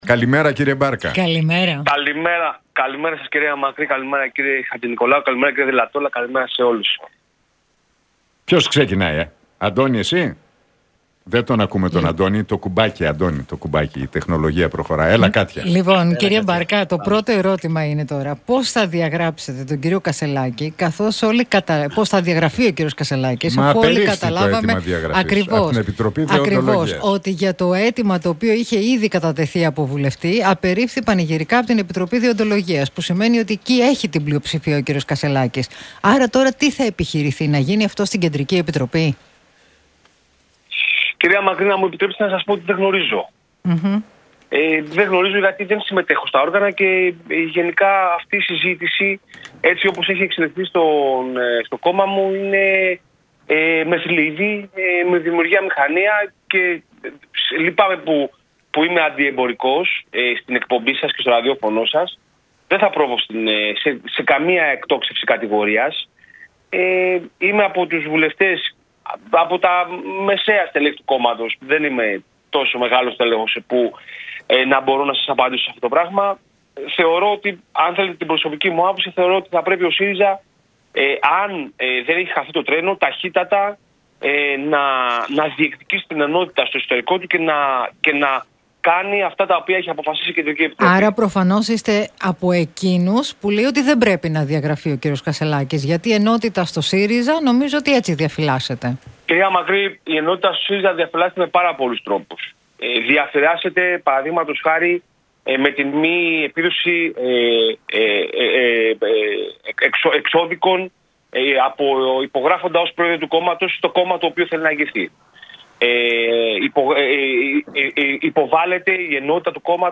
Μπάρκας στον Realfm 97,8: Αυτά που ακούγονται στο εσωτερικό του ΣΥΡΙΖΑ δημιουργούν προϋποθέσεις πλήρους ρευστοποίησης του κόμματος
Για τις εξελίξεις και το πολεμικό κλίμα στον ΣΥΡΙΖΑ, την υποψηφιότητα του Στέφανου Κασσελάκη αλλά και την επόμενη ημέρα μίλησε ο βουλευτής του ΣΥΡΙΖΑ, Κώστας